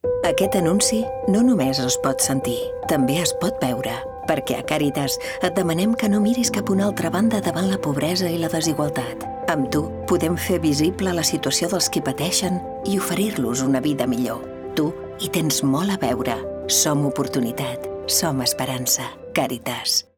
Cunya Ràdio